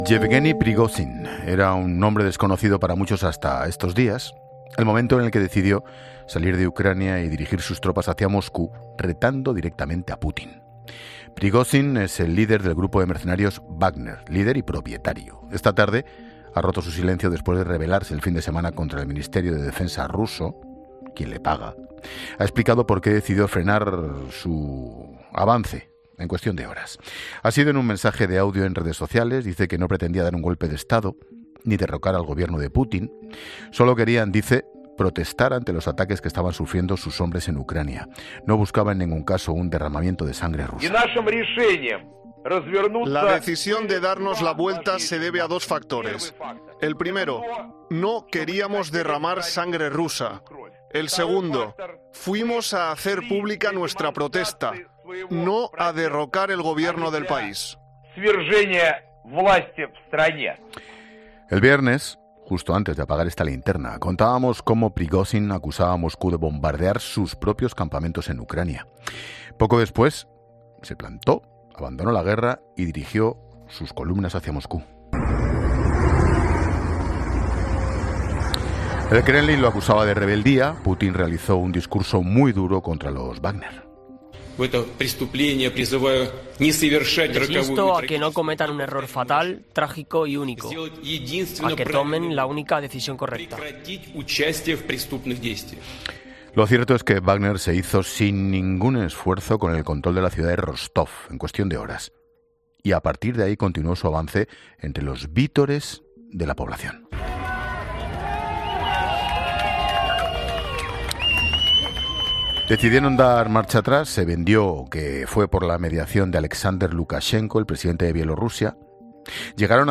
Monseñor Juan José Aguirre, obispo de Bangassou, describe en COPE cómo se comportan los miembros del Grupo Wagner cuando se hacen con el control de un...